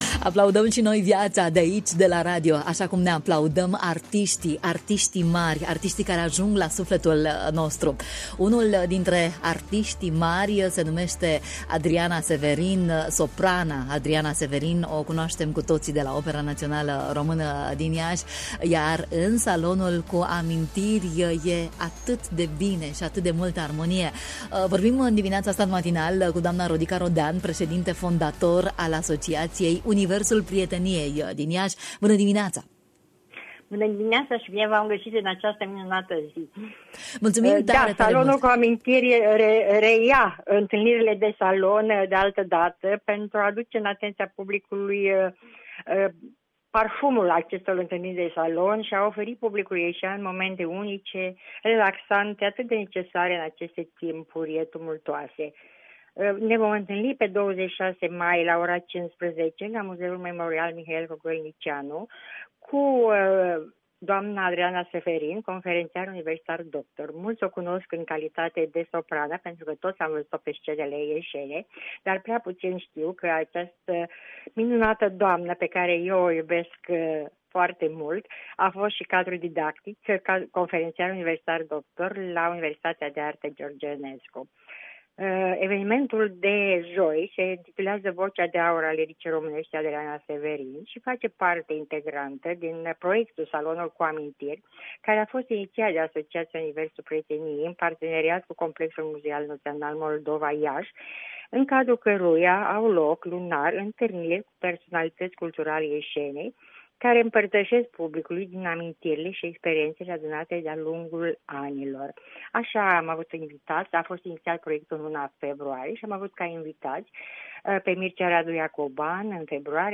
În direct, prin telefon